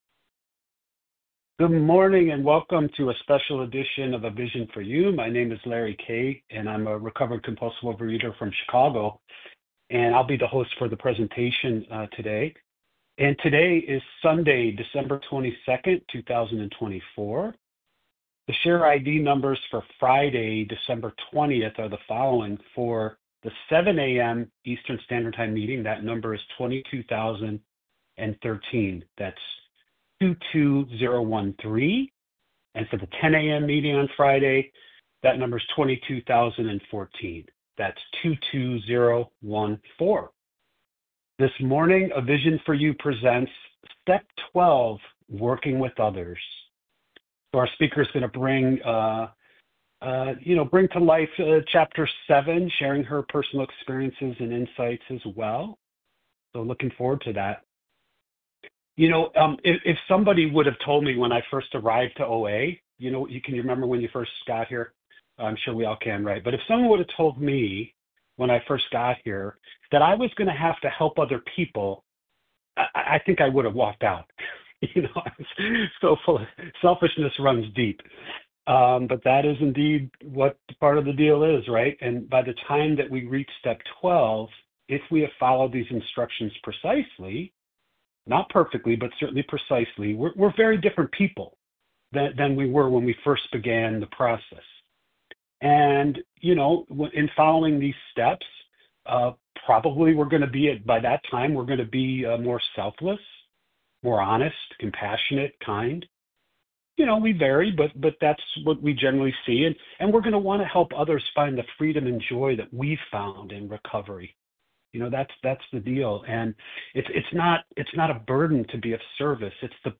Overeaters Anonymous members share their experience, strength and hope on a number of different topics.